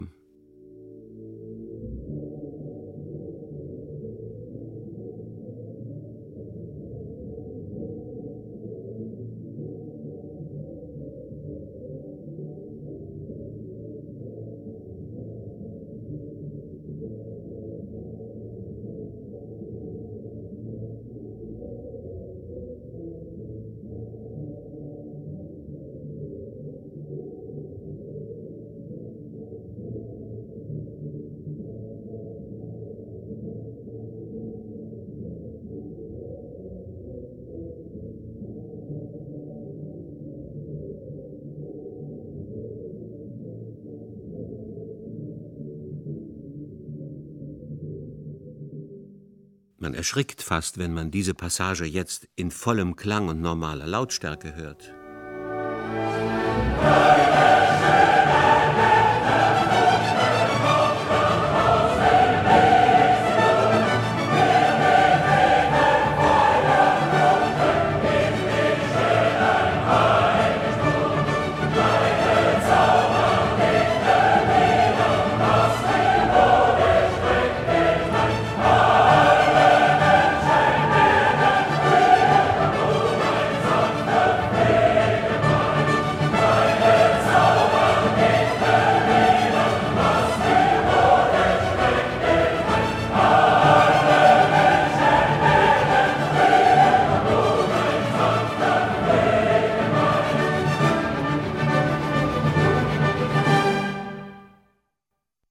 L. Bernstein (2:50; 3,7 MB) Sinfonie op.125, Ode an die Freude (gefiltert und ungefiltert) Berliner Philharmoniker, Ltg. Herbert von Karajan (1:46; 2,2 MB)